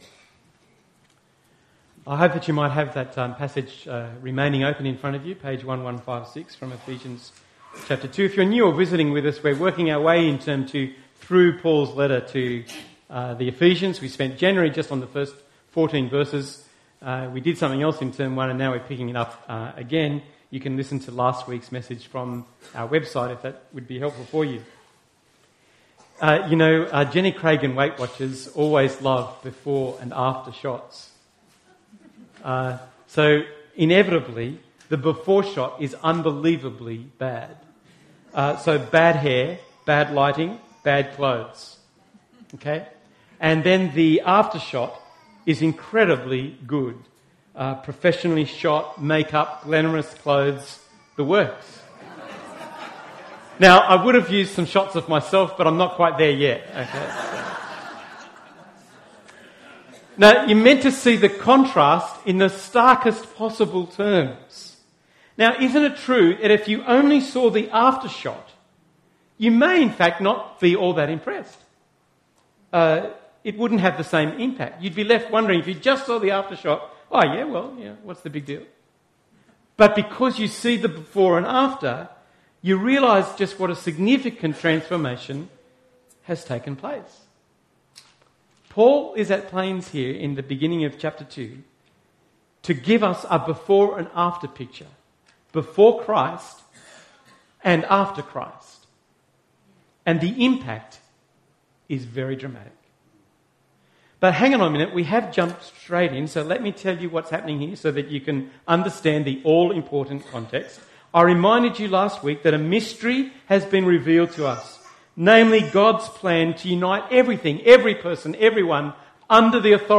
The second sermon in our new series “Living (the) Gospel” from Paul’s letter to the Ephesians. Today’s passage: Ephesians 2:1-10. Audio recorded at our Tewantin service.